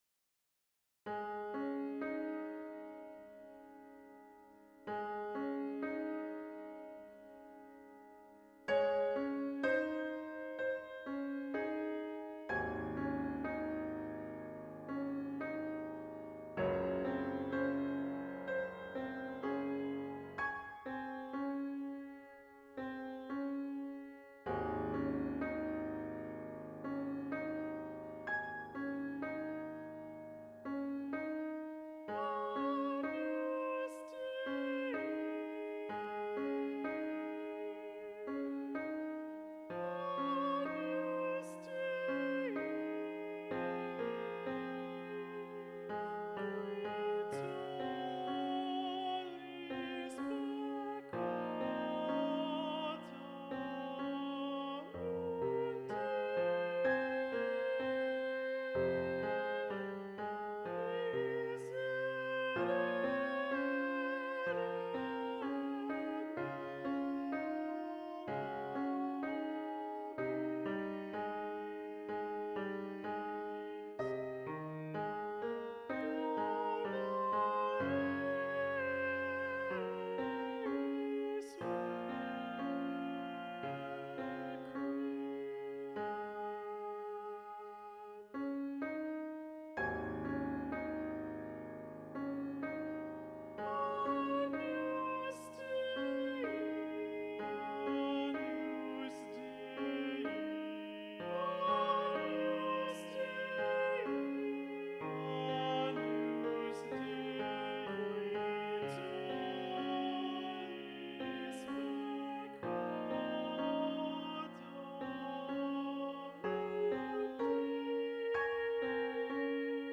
Video Only: Agnus Dei - Bass 2 Predominant